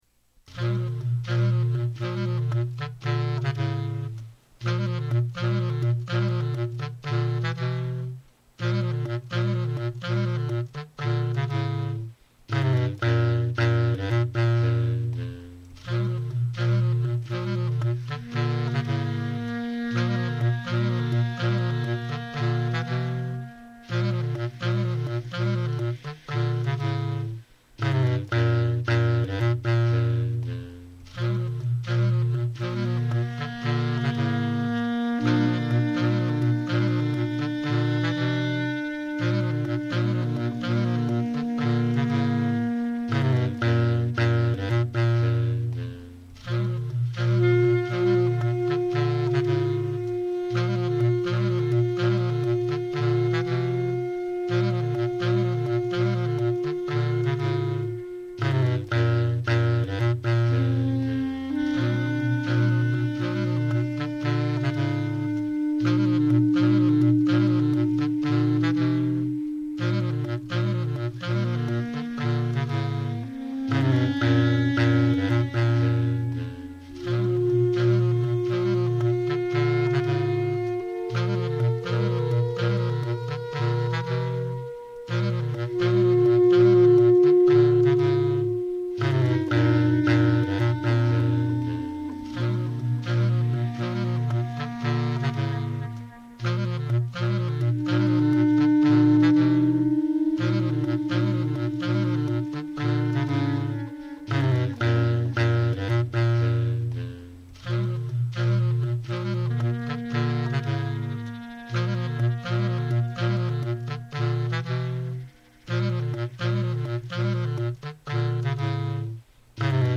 Recorded live at home in Manhattan.
alto clarinet, samplers